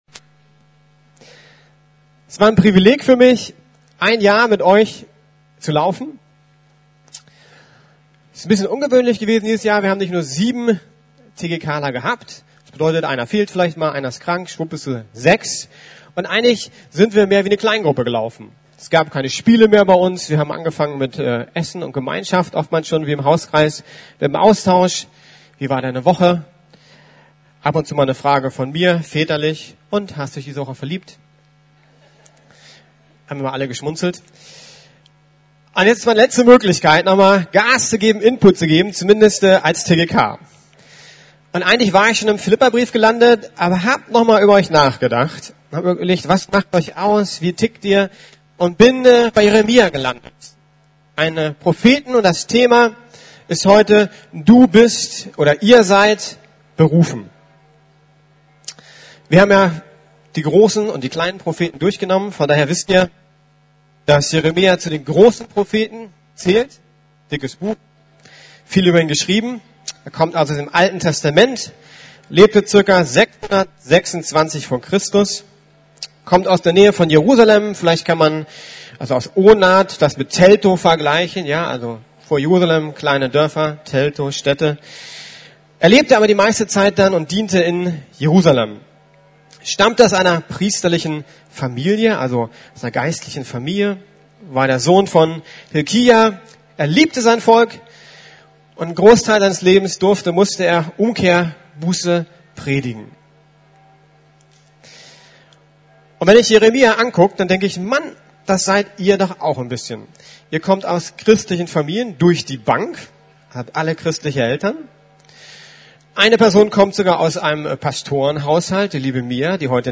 Du bist berufen - TGK Gottesdienst